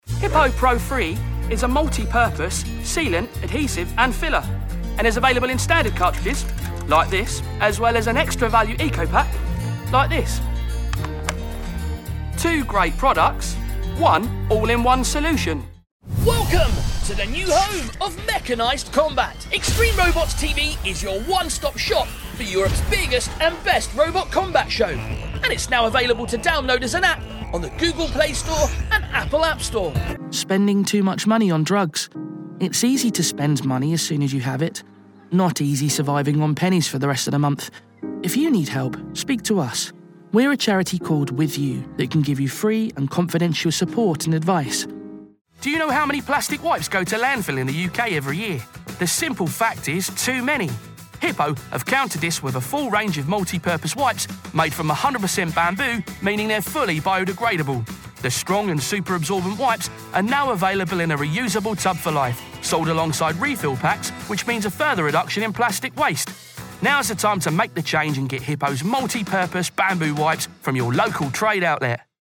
Teens-20s. Young, fun. Fresh with a light touch. Good singer. Home Studio.
Commercials
• Native Accent: Southern English